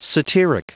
Prononciation du mot satiric en anglais (fichier audio)
Prononciation du mot : satiric